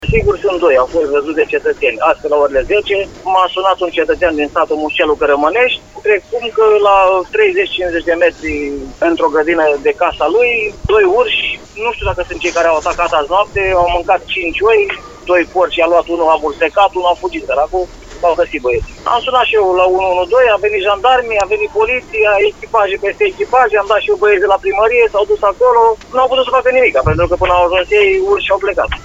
Vorbim aici despre polițiști, jandarmi, iar autoritatea locală a pus la dispoziție câțiva oameni pentru a alunga animalele sălbatice, după cum ne-a declarat primarul comunei Colți, Pavel Rădulescu:
Primar-Colti-1.mp3